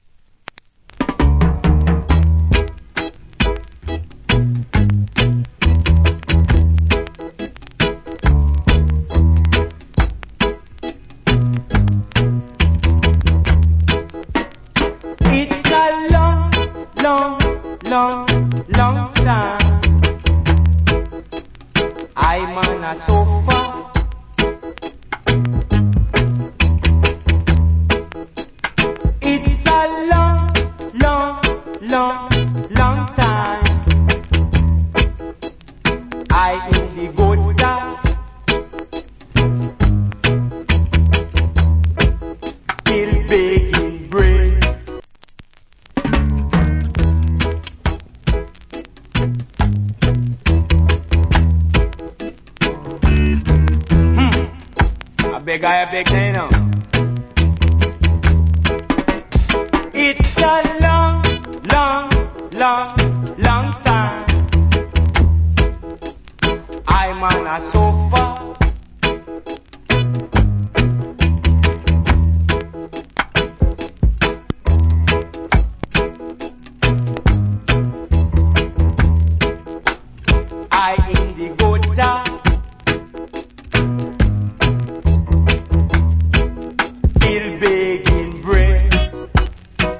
Nice Roots Rock Vocal.